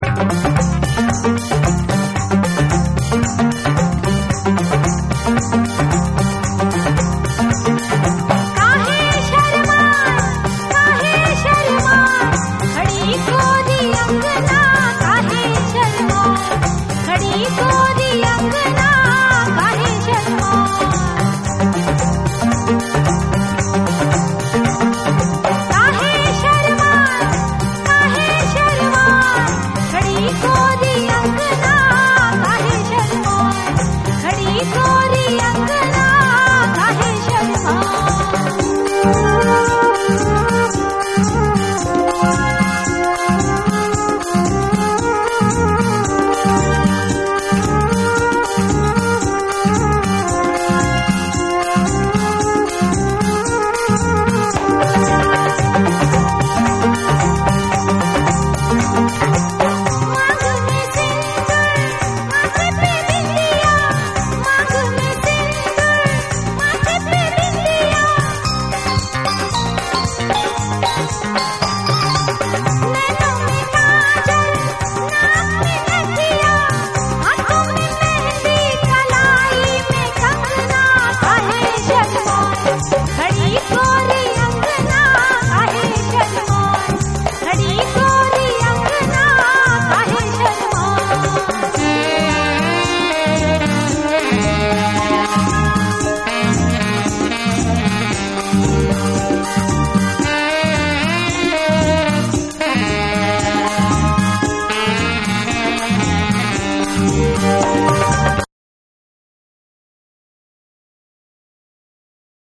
サイケデリックなシンセ・アレンジが施されたボリウッド・ソカ・ディスコ